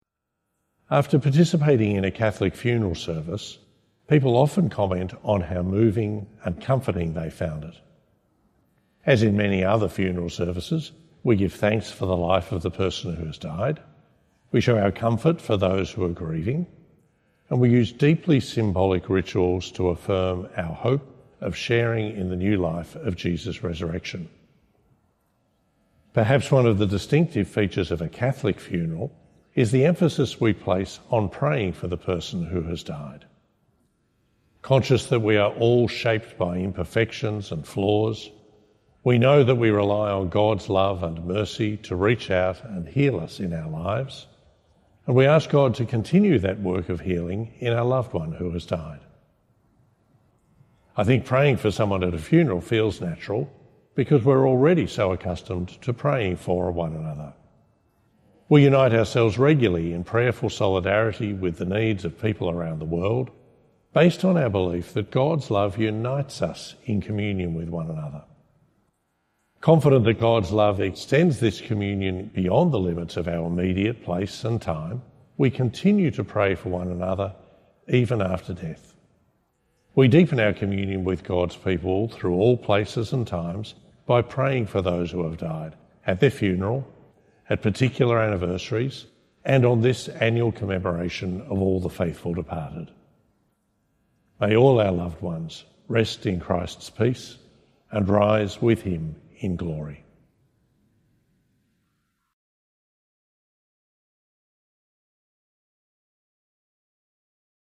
All Souls’ Day - Two-Minute Homily: Archbishop Shane Mackinlay
Two-Minute Homily by Archbishop Shane Mackinlay for All Souls’ Day (The Commemoration of All the Faithful Departed) 2025, Year C. "Confident that God’s love extends this communion beyond the limits of our immediate place and time, we continue to pray for one another even after death."